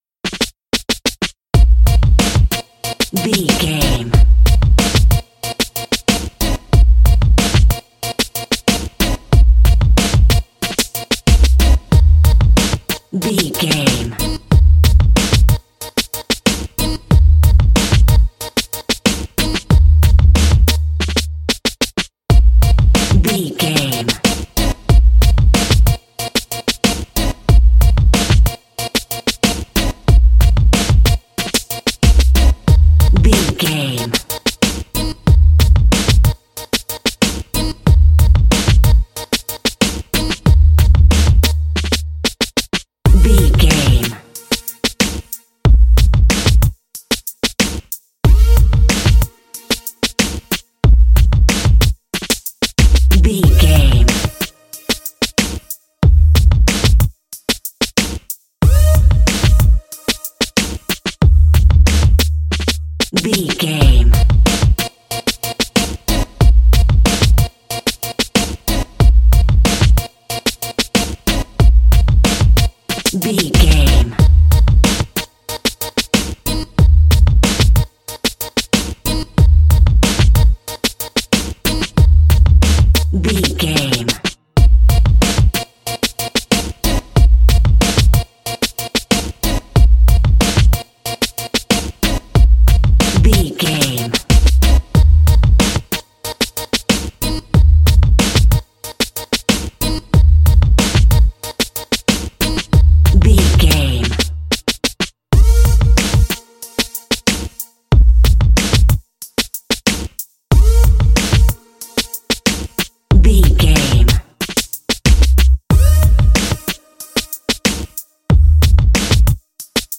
Aeolian/Minor
B♭
synthesiser
drum machine
hip hop
Funk
neo soul
acid jazz
confident
energetic
bouncy
funky